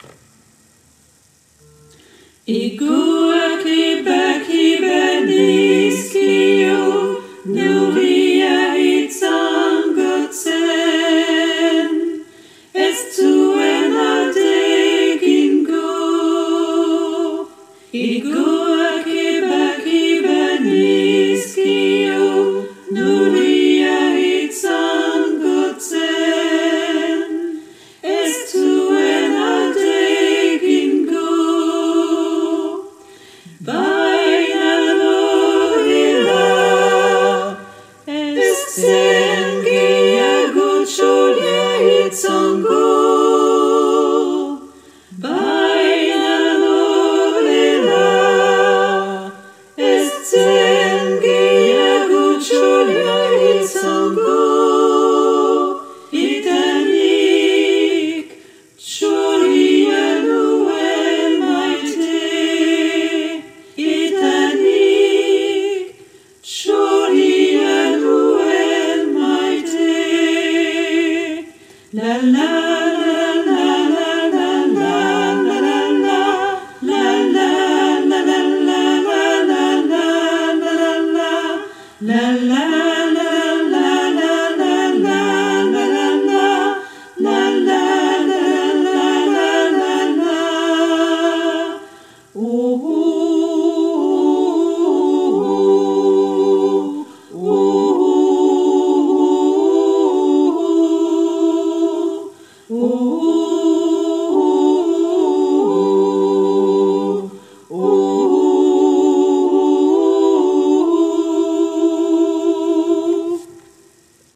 - Chant pour choeur à 4 voix mixtes (SATB)
Tutti (version chantée)